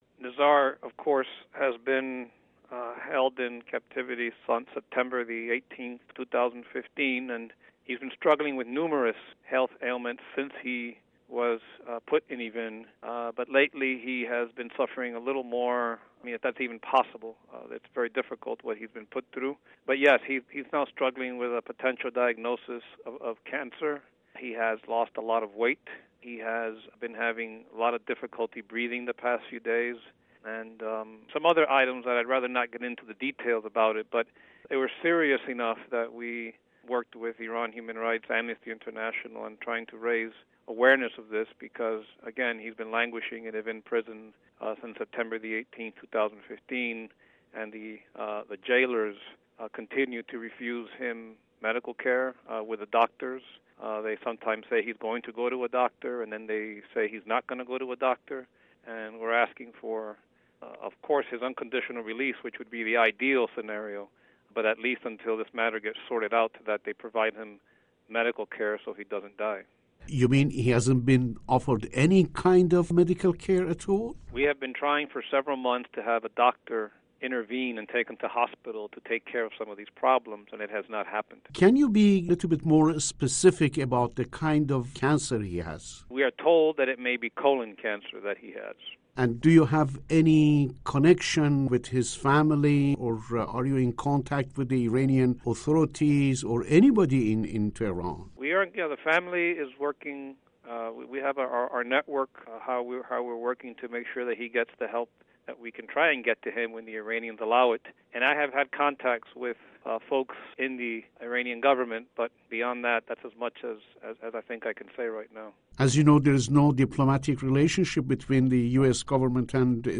Radio Farda's Exclusive interview